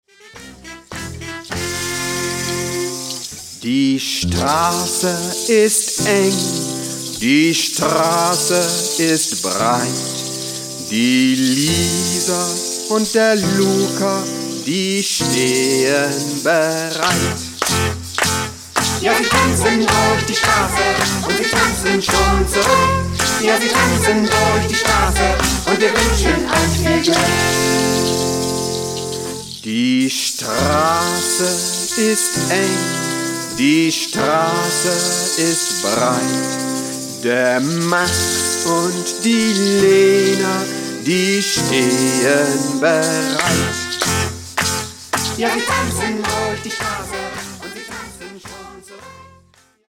Lieder zum Kreisen, Springen, Tanzen und Singen